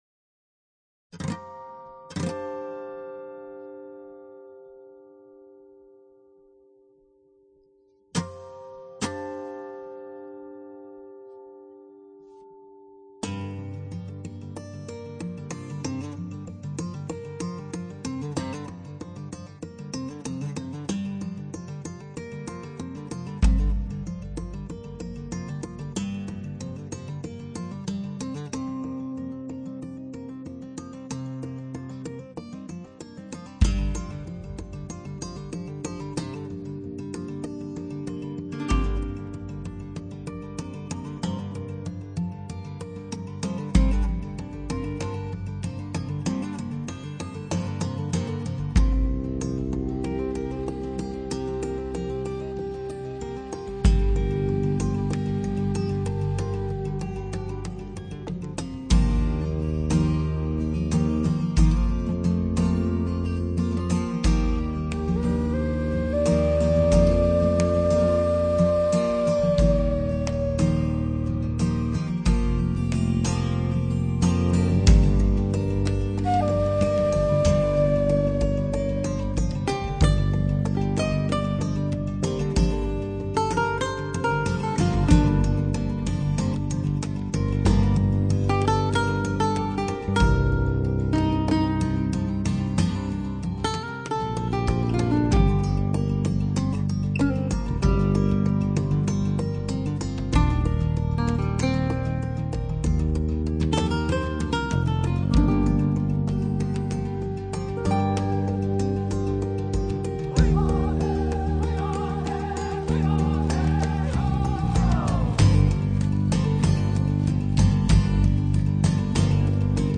鼓乐震天，加上人声音效仿如置身现场；鼓声劲度十足，小心烧机；吉他及各种乐器产生独特的化学作用。
他的演唱充满丛林草原的沧桑，虽然沙哑粗犷，但别具自然朴实的魅力。
电子合成器的加入带来的是整体听感的丰满，而且低频的下潜也更为深沉有力，弹跳力和凝聚感非常出色。